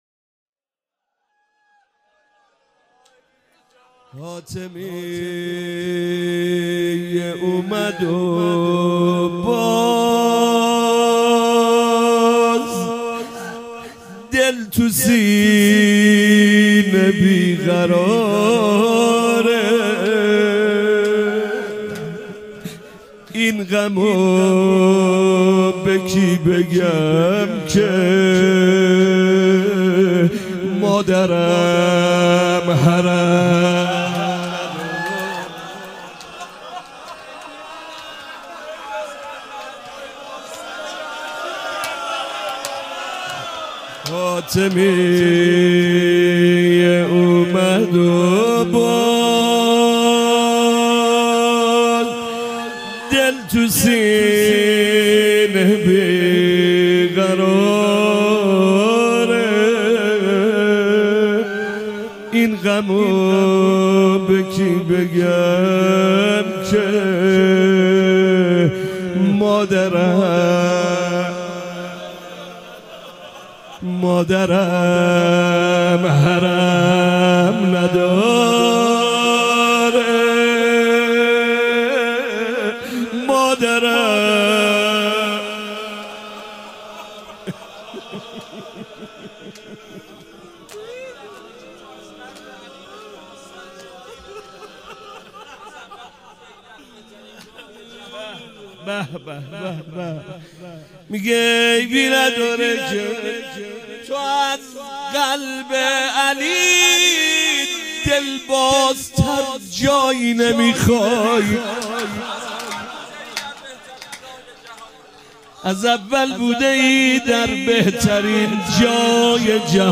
فاطمیه 96 - شب اول - زمزمه - فاطمیه اومد و باز